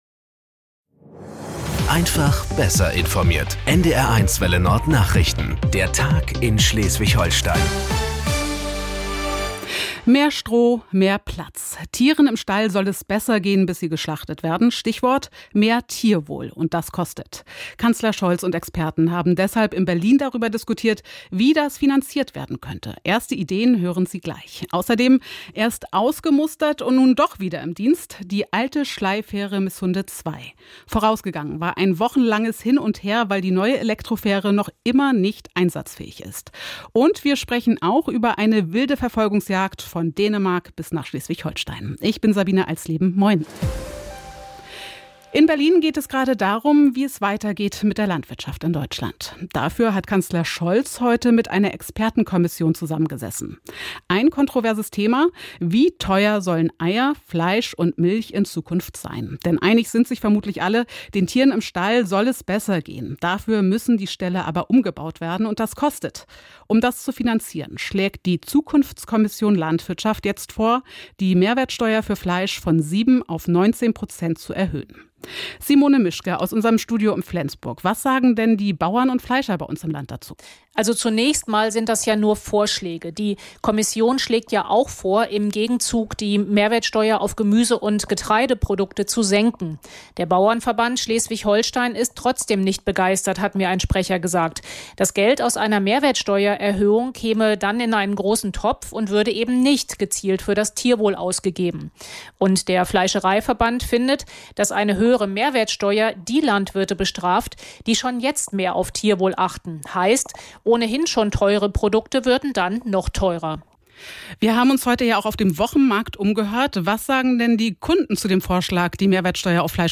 Nachrichten 08:00 Uhr - 12.04.2024